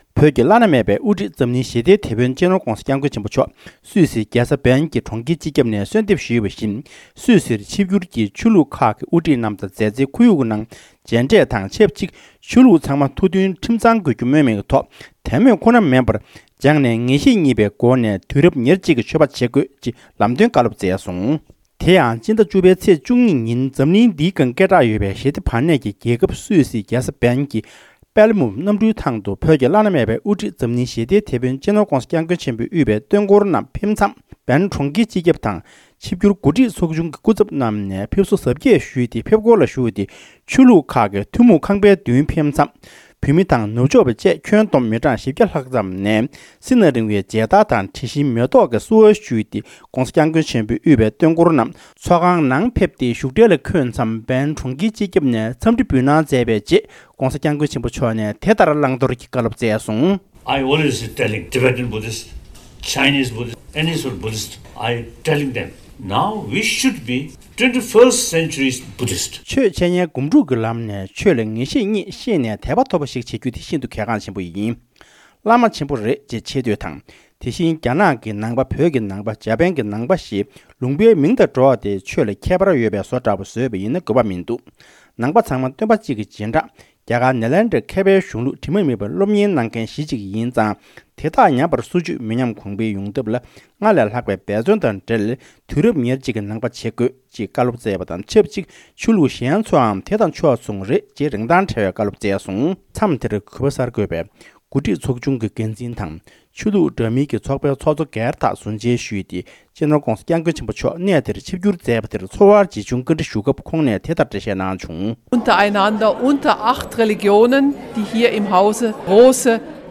ས་གནས་གསར་འགོད་པས་བཏང་བའི་གནས་ཚུལ་ལ་གསན་རོགས་ཞུ།
སྒྲ་ལྡན་གསར་འགྱུར།